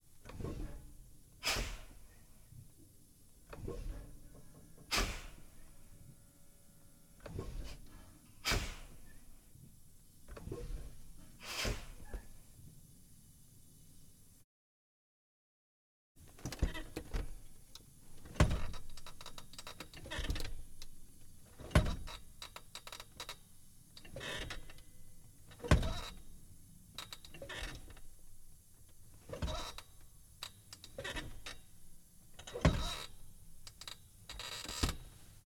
Pole Position - Peterbilt 359 Detroit 1968